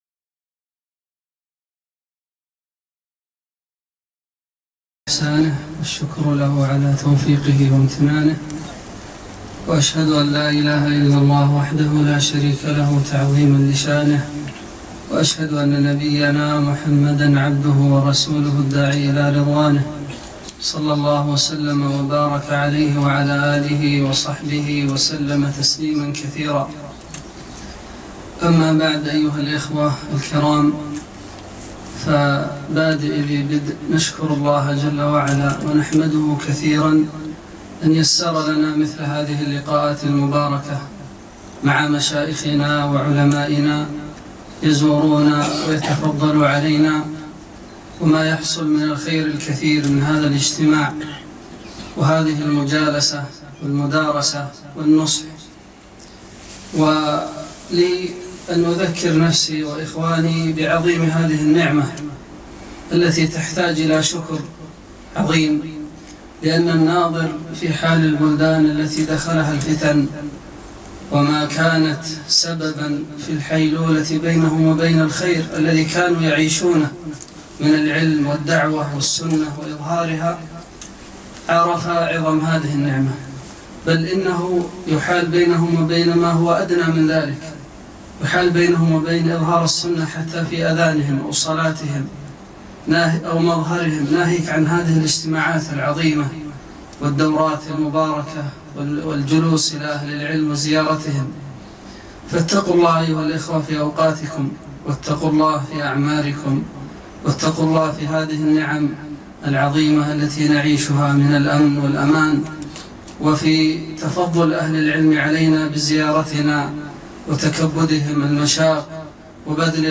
محاضرة - واجعلنا للمتقين إماما